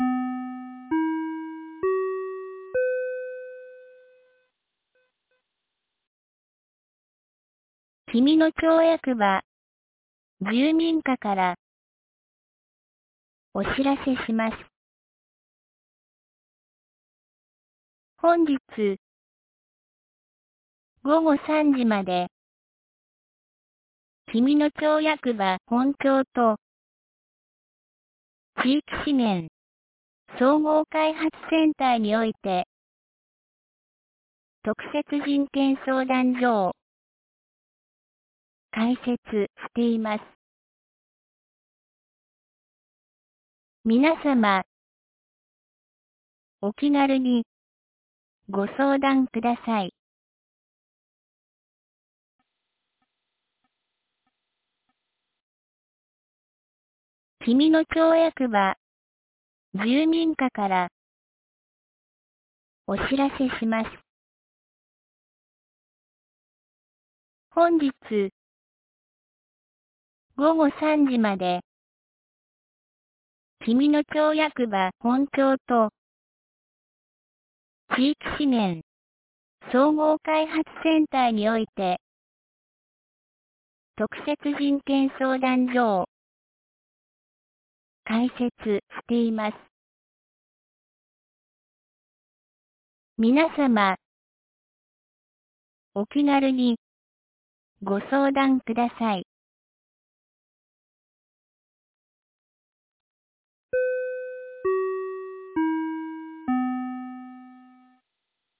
2023年06月01日 12時32分に、紀美野町より全地区へ放送がありました。